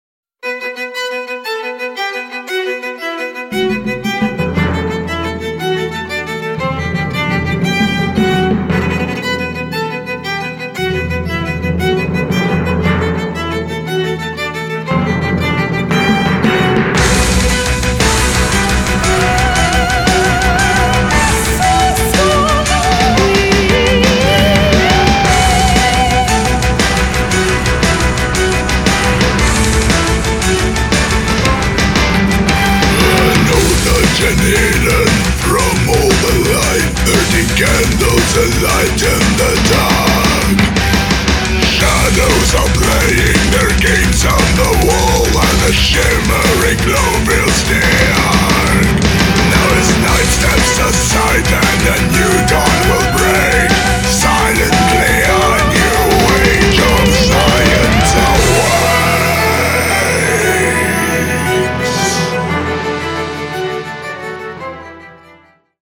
• Качество: 200, Stereo
скрипка
Metal
symphonic
симфонический метал
Немецкий музыкальный проект исполняющий симфонический метал